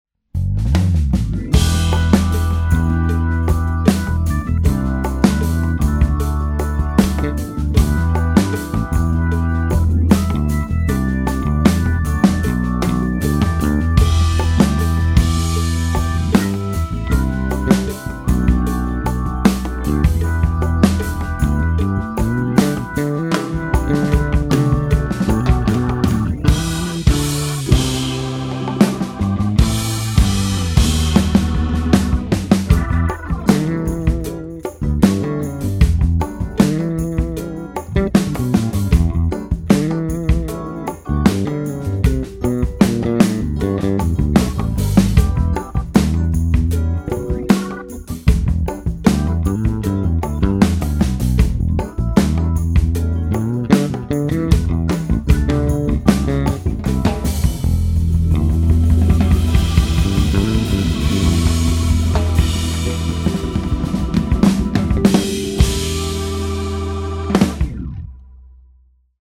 Just to give you an idea of how expressive the library is, the above demo was played entirely live from a MIDI keyboard, straight out of the box with no external effects used.